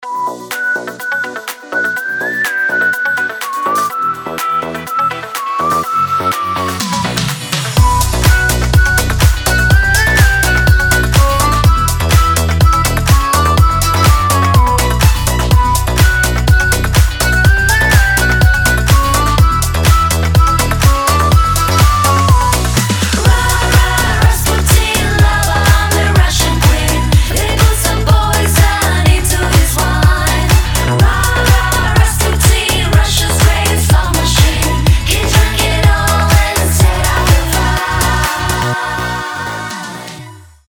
• Качество: 320, Stereo
свист
диско
веселые
заводные
Cover
Euro House
ремиксы